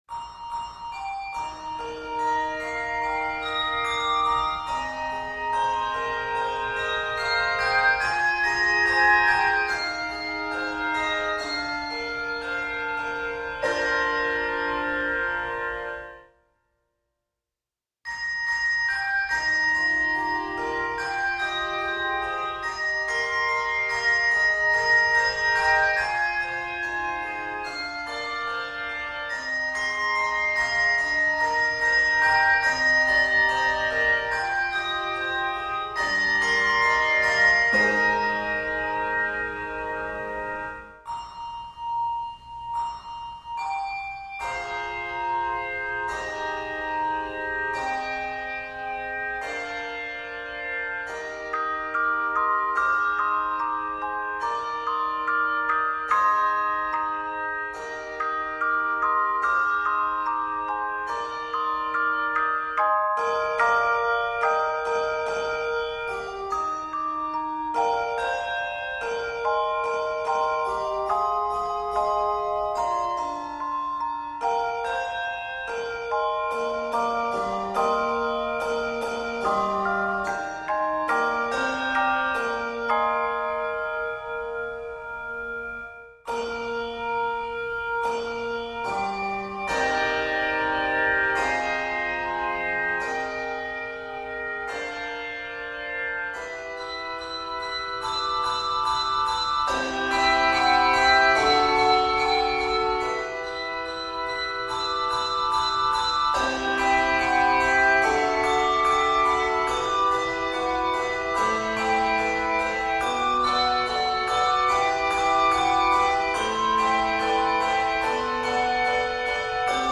This 70 measure work is scored in Eb Major and e minor.